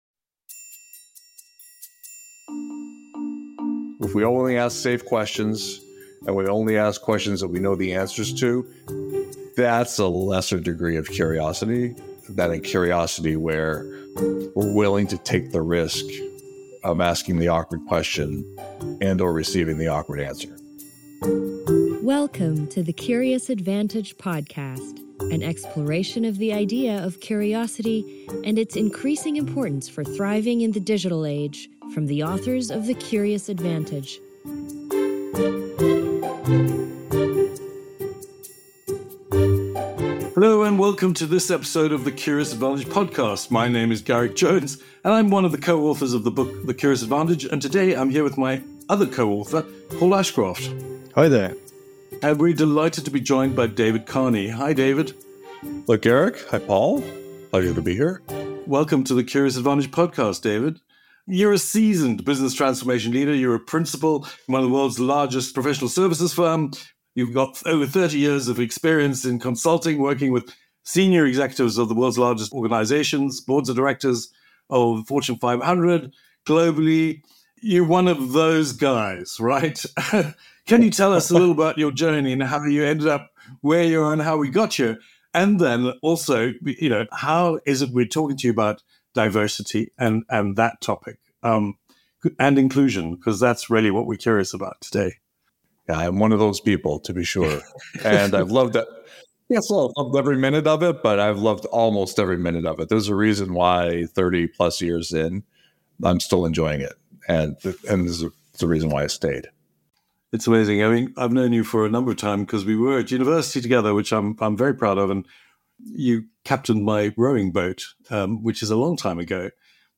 Join us for an inspiring conversation